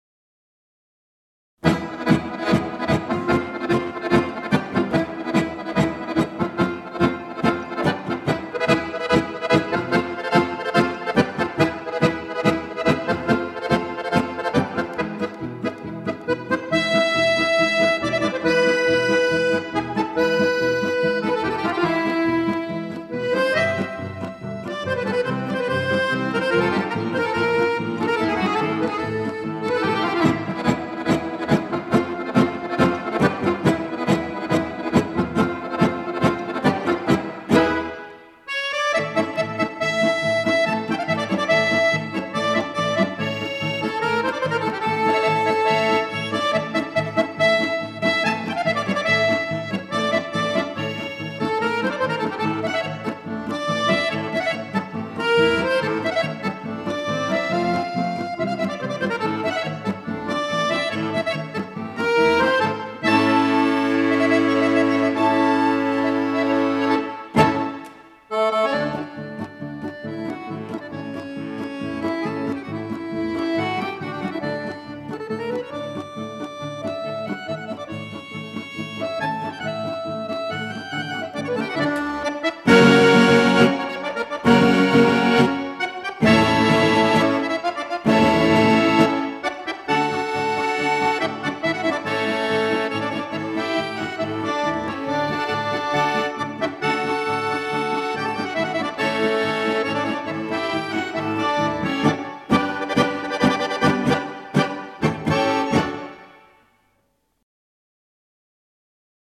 Professional Full Size Accordion, Italian and French Musette Tuning, New